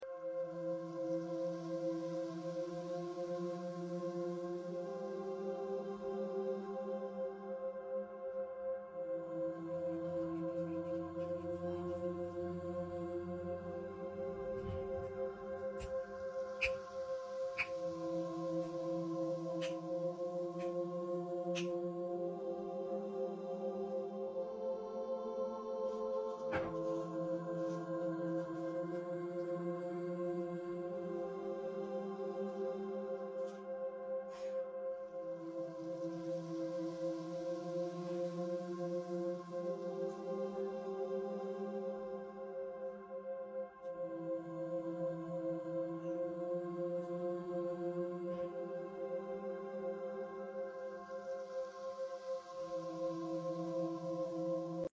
💸 Money Block Remover ASMR sound effects free download